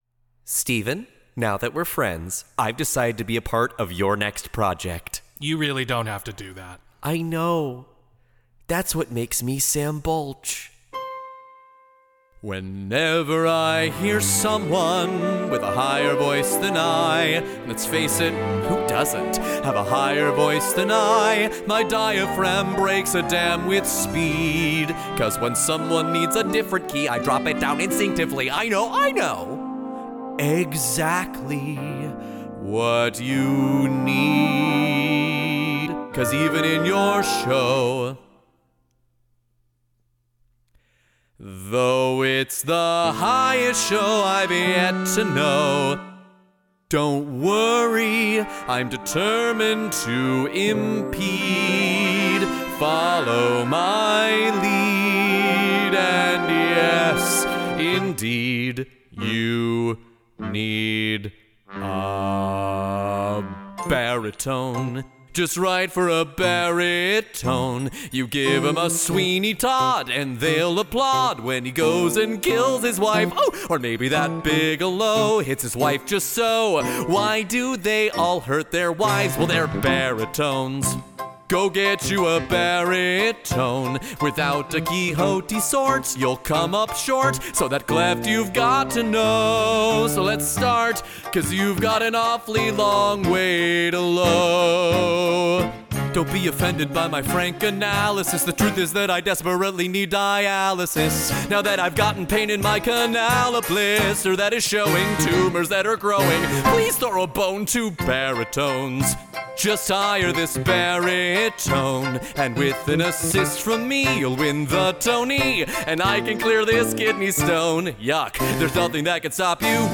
This demo is the result of that inquiry.